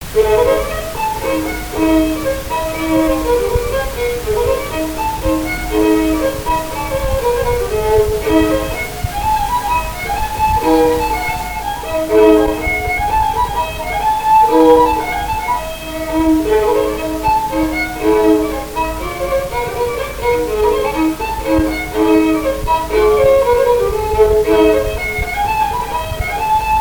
danse : branle : avant-deux
Répertoire du violoneux
Pièce musicale inédite